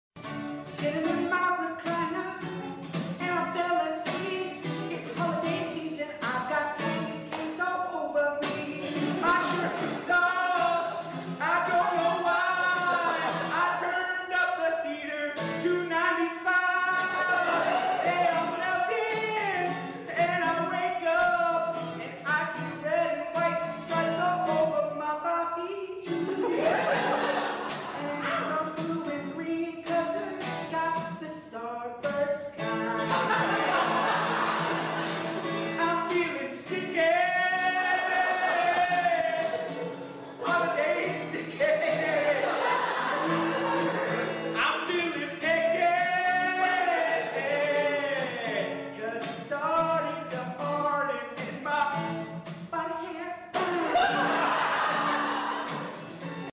Improv Song